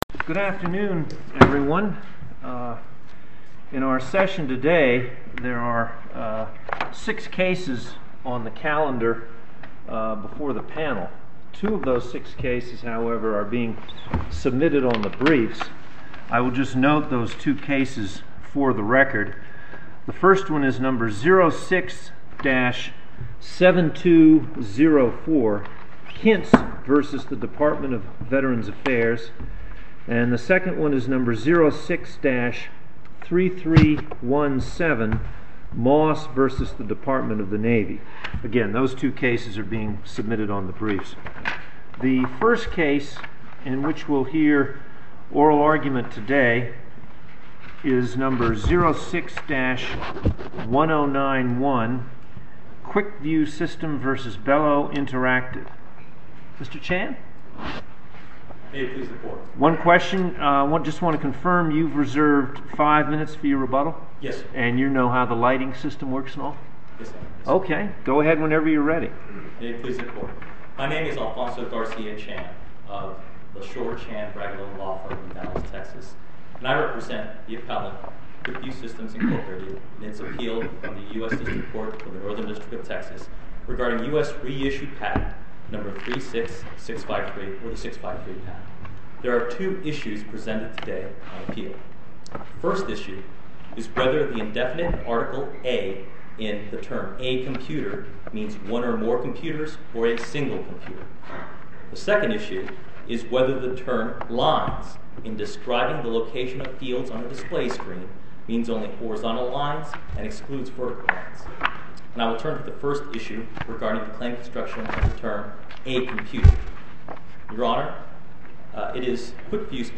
Oral argument audio posted: Quickview System v Belo Interactive (mp3) Appeal Number: 2006-1091 To listen to more oral argument recordings, follow this link: Listen To Oral Arguments.